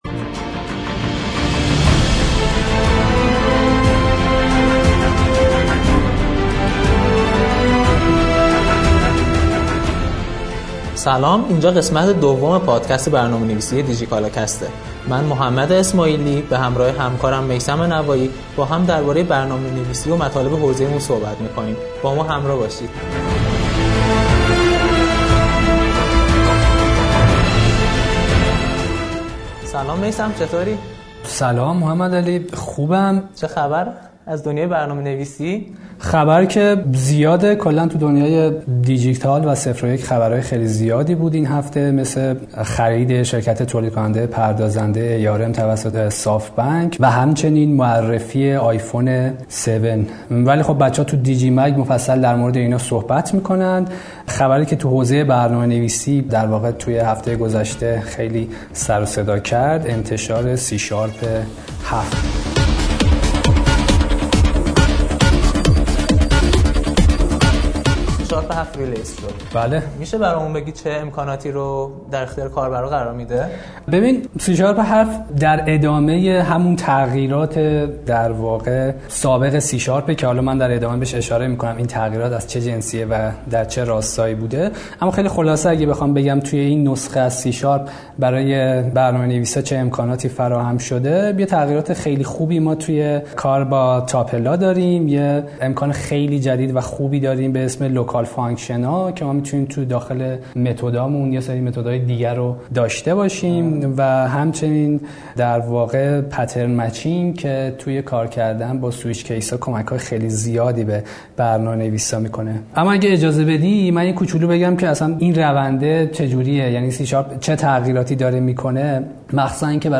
همچنین گپ‌وگفتی هم درباره‌ی یکی از شیوه‌های پرکاربرد کسب درآمد از طریق برنامه‌نویسی یعنی «آزادکاری» خواهیم داشت و به این موضوع می‌پردازیم که آزادکاری چه مزایا و معایبی دارد.